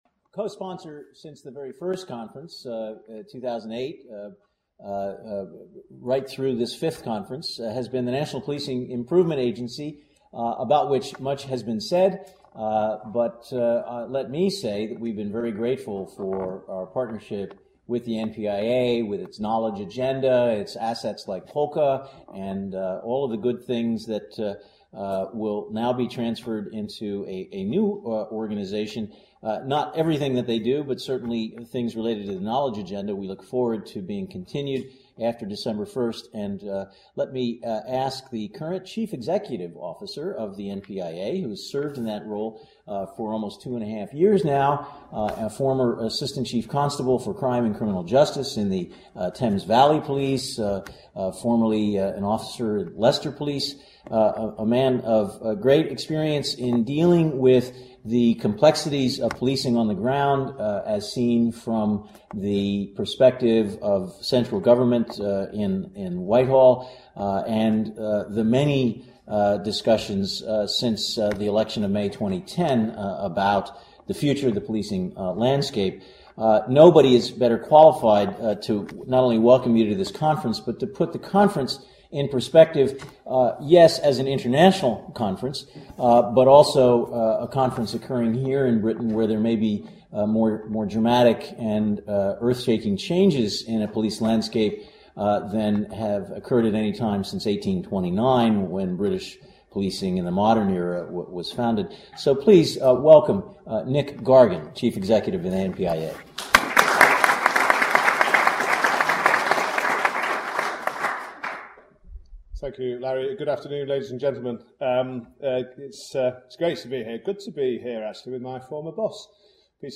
Day 1: 'Welcome and Introduction to the Conference': Nick Gargan, Chief Constable, NPIA, UK
The Institute of Criminology holds an annual international conference on Evidence Based Policing, Chaired by Professor Lawrence Sherman in association with the National Policing Improvement Agency (NPIA) and the Society for Evidence Based Policing (SEBP).